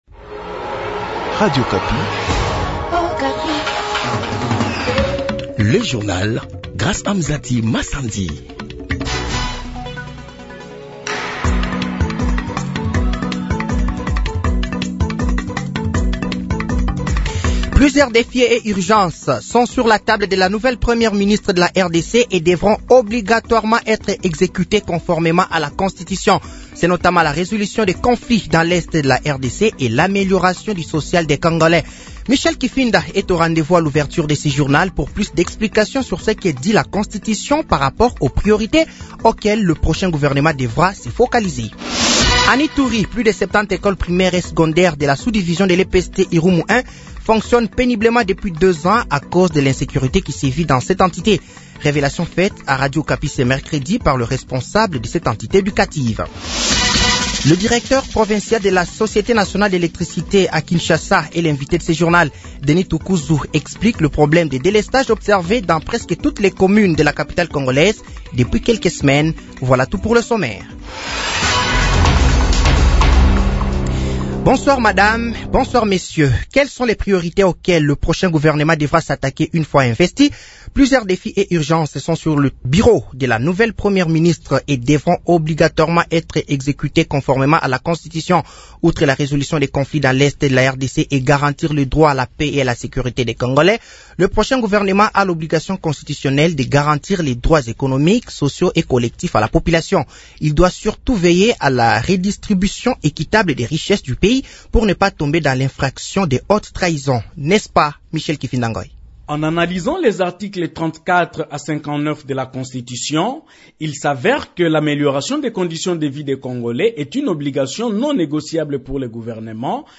Journal français de 18h de ce mercredi 03 avril 2024